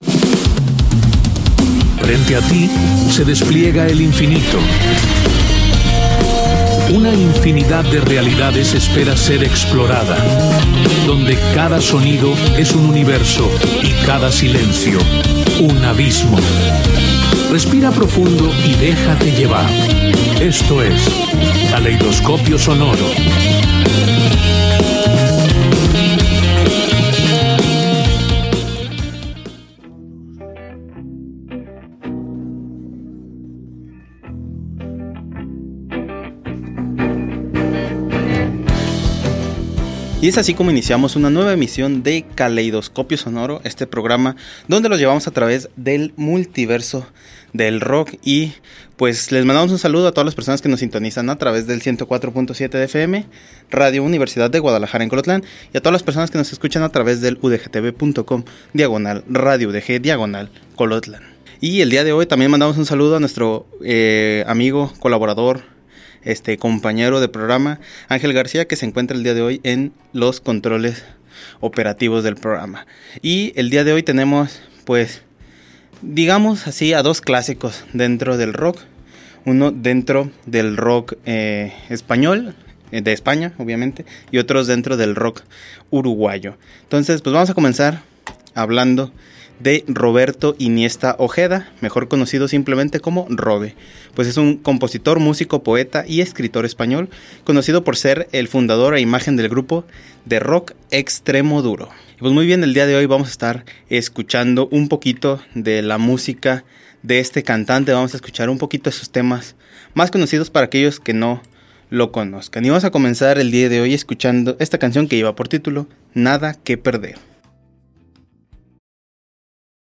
Somos un programa dedicado a descubrir y recordar a aquellas bandas de Rock en español que dejaron huella en la historia de este género, y también a aquellas que comienzan a dejar huella. Buscamos esos covers de temas de otros géneros, traídos al mundo del rock. También te contamos aquellas historias detrás de cómo se compuso, como se grabó o cuál fue la anécdota de aquellas canciones que todos conocemos.